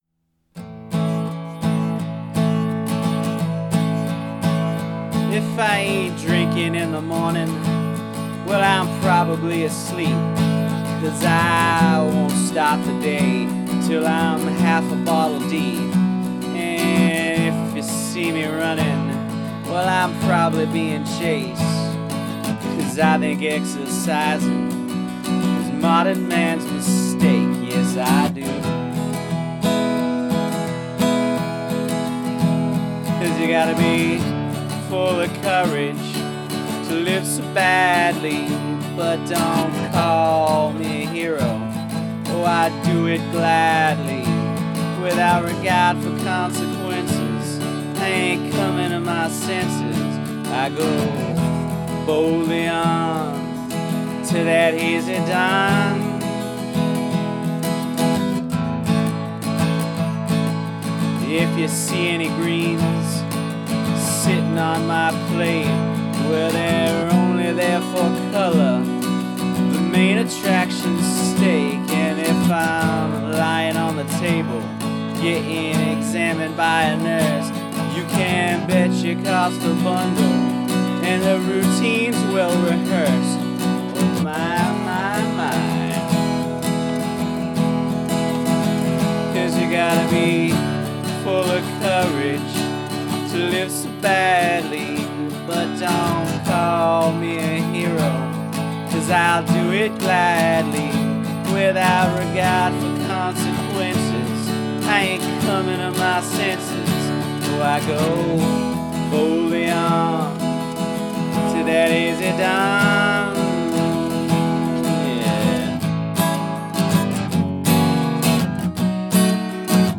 Must include a spoken word bridge